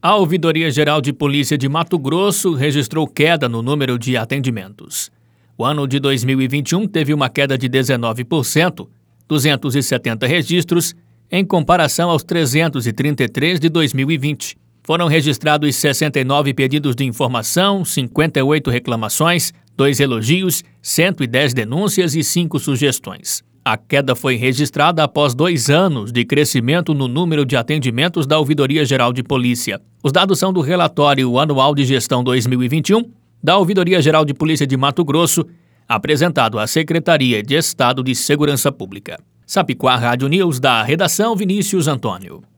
Boletins de MT 15 fev, 2022